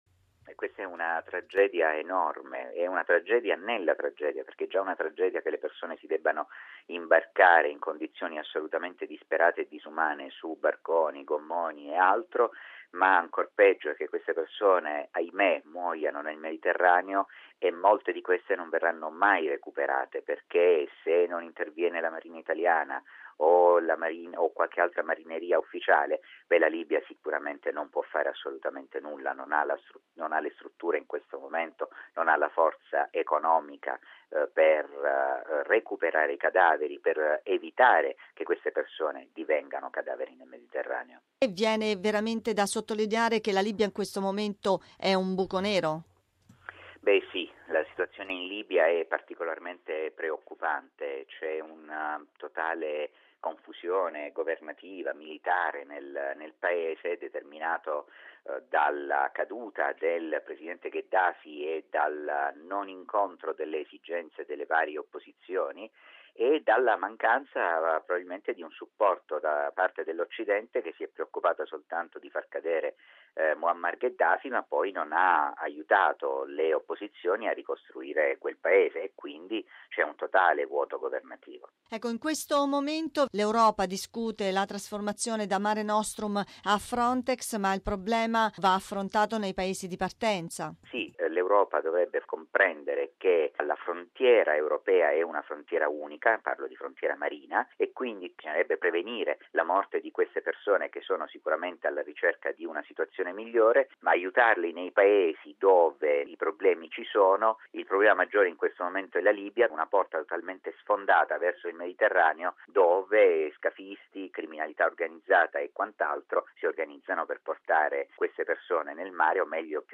Nell'intervista